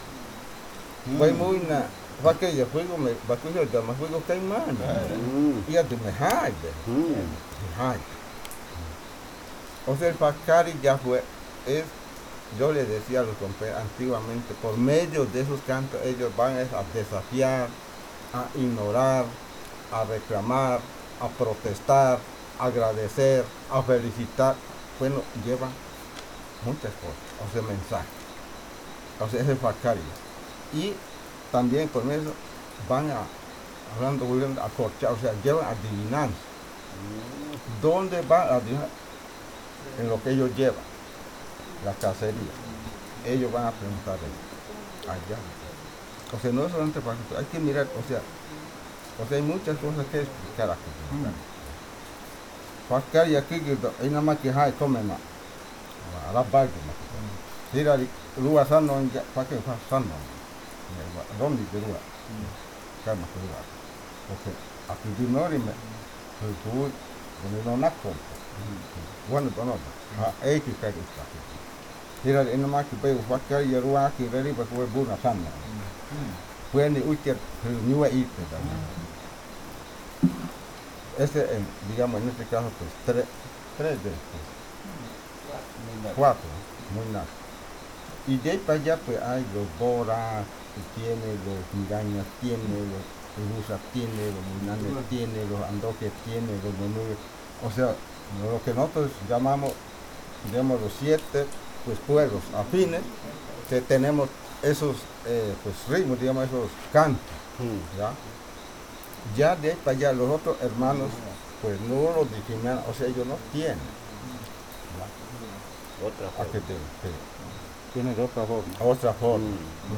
Explicación sobre los cantos de fakariya
Leticia, Amazonas, (Colombia)